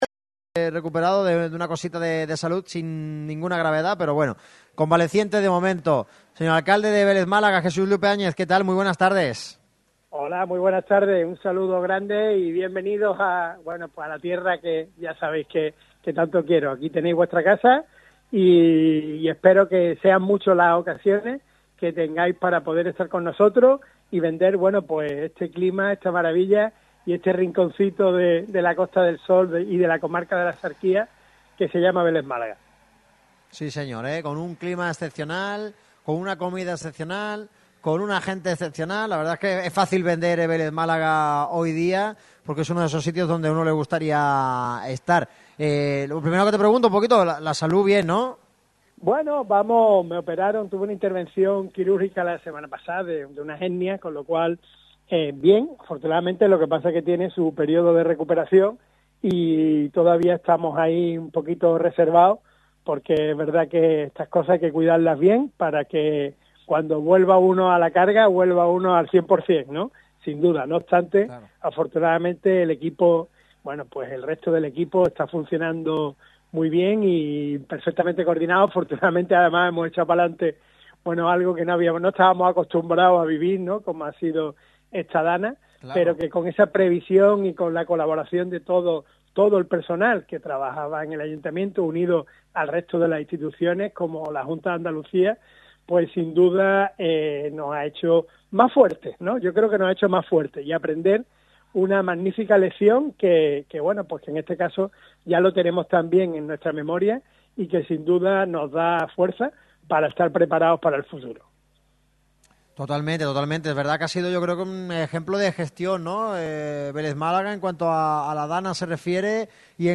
Jesús Lupiáñez, alcalde de Vélez-Málaga, ha pasado por los micrófonos de Radio MARCA Málaga en el programa especial que ha tenido lugar en el Fernando Hierro